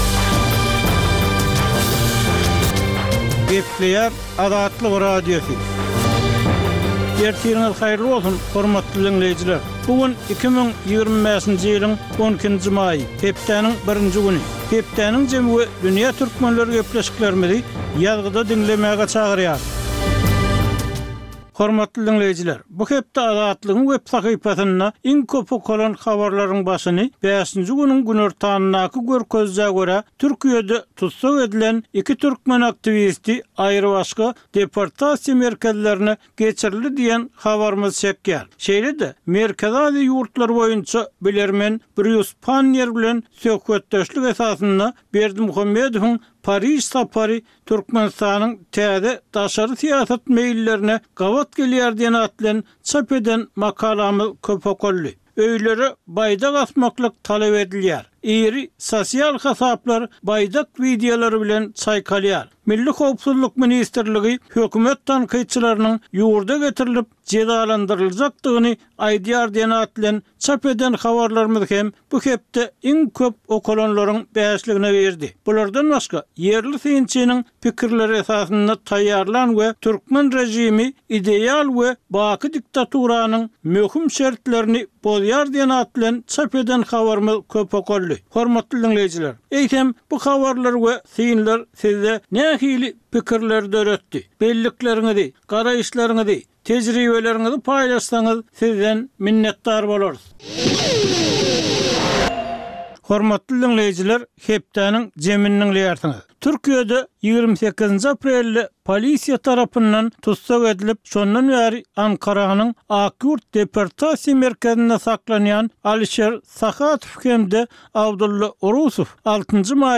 Täzelikler